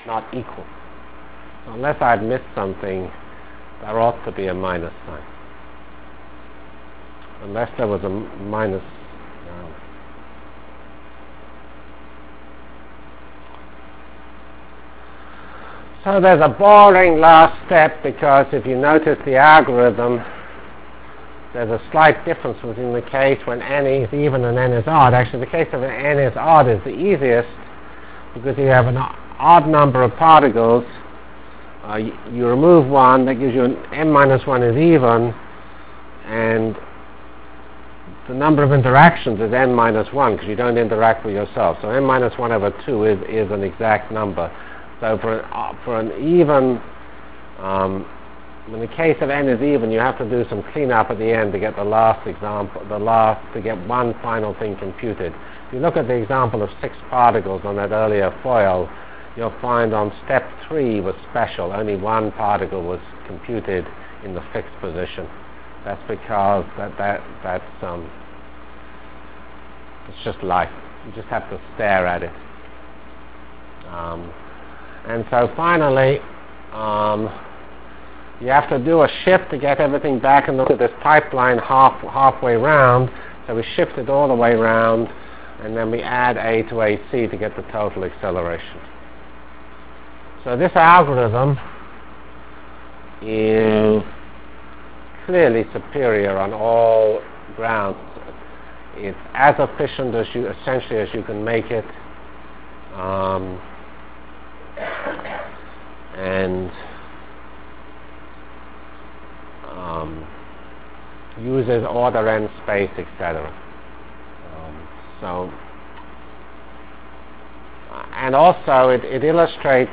From CPS615-End of N-Body Discussion and Beginning of Numerical Integration Delivered Lectures of CPS615 Basic Simulation Track for Computational Science -- 15 October 96. by Geoffrey C. Fox *